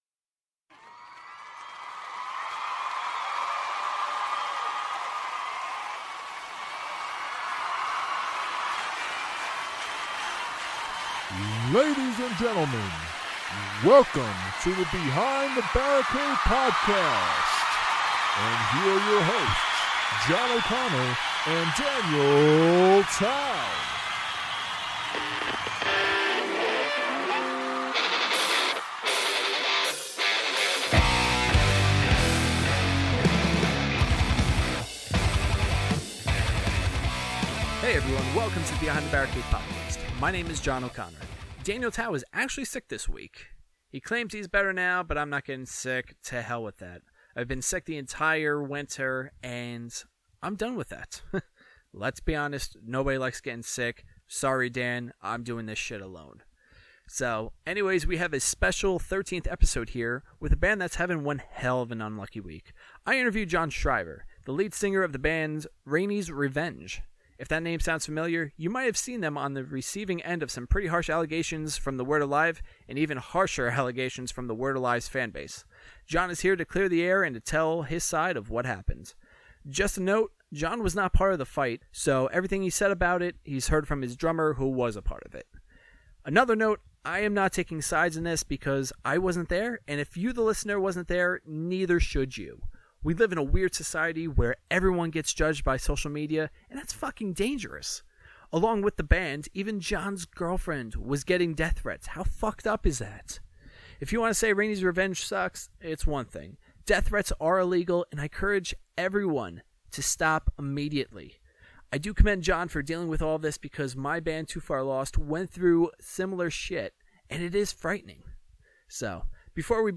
Just a quick note, this interview was straight down the middle.